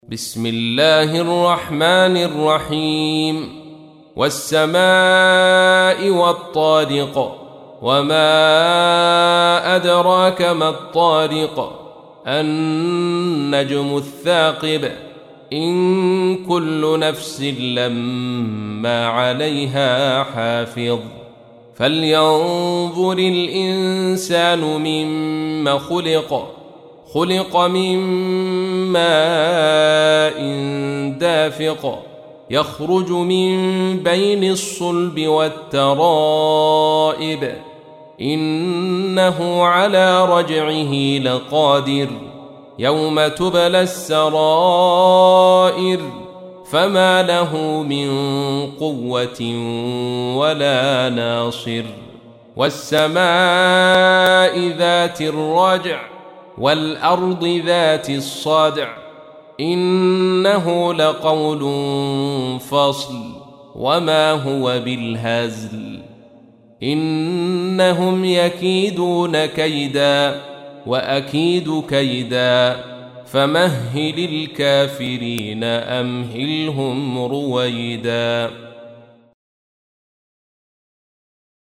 تحميل : 86. سورة الطارق / القارئ عبد الرشيد صوفي / القرآن الكريم / موقع يا حسين